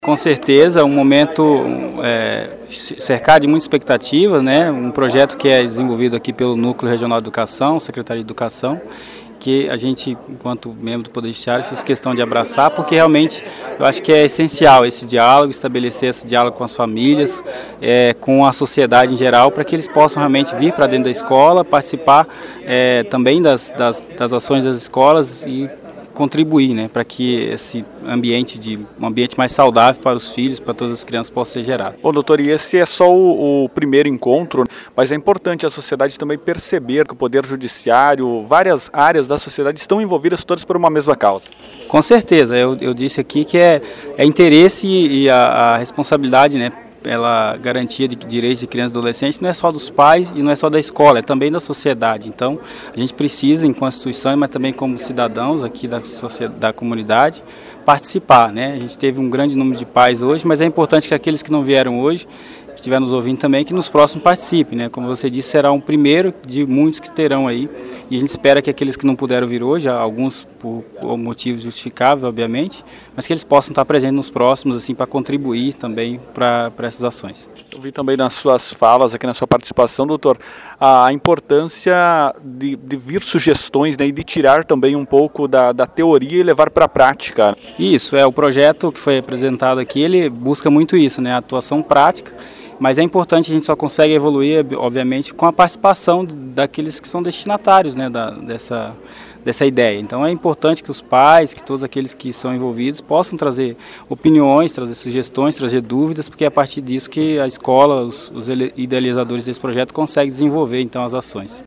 O Juiz da Comarca de Ampére, Dr Cristiano Diniz da Silva, fala da importância do projeto.